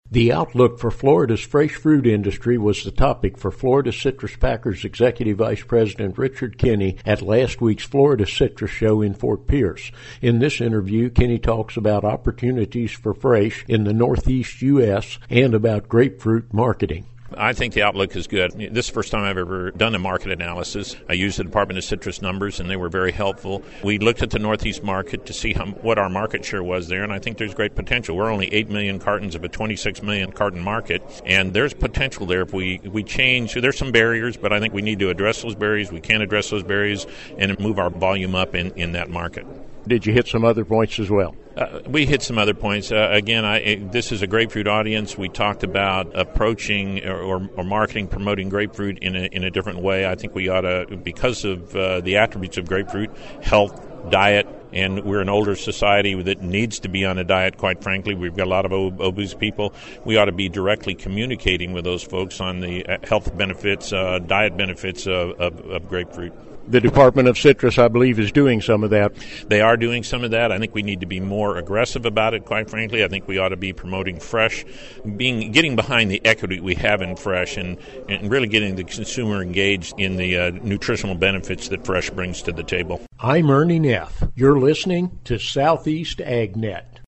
at the recent Florida Citrus Show in Fort Pierce.